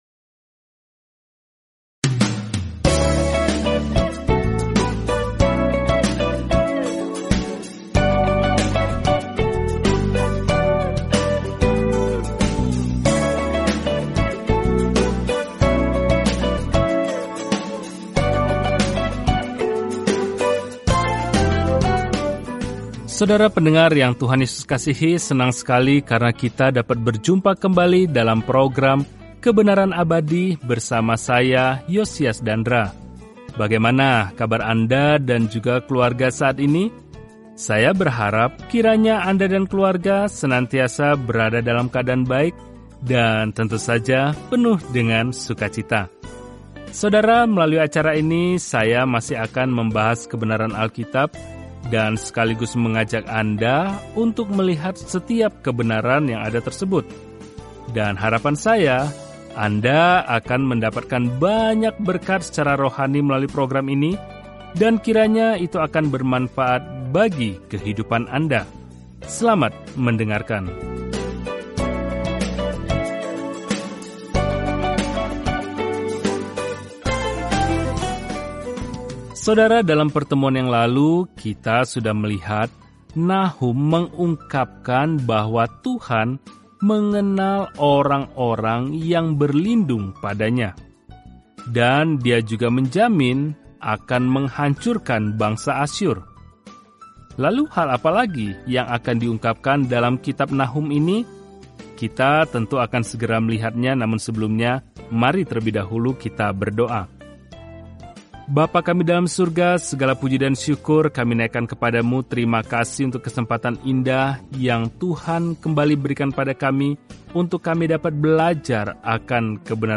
Firman Tuhan, Alkitab Nahum 1:11-15 Hari 3 Mulai Rencana ini Hari 5 Tentang Rencana ini Nahum, yang namanya berarti penghiburan, membawa pesan penghakiman kepada musuh-musuh Tuhan dan membawa keadilan dan harapan bagi Israel. Perjalanan sehari-hari melalui Nahum sambil mendengarkan studi audio dan membaca ayat-ayat tertentu dari firman Tuhan.